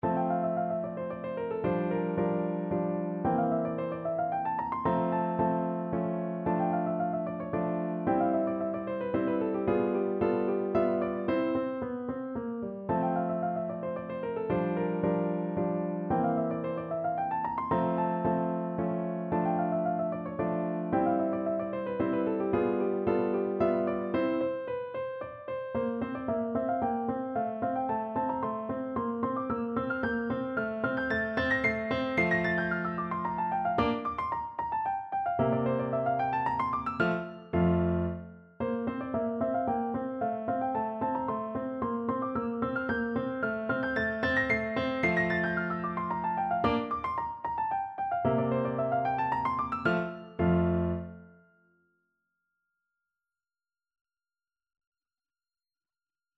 No parts available for this pieces as it is for solo piano.
Moderato (=112)
3/4 (View more 3/4 Music)
Piano  (View more Easy Piano Music)
Classical (View more Classical Piano Music)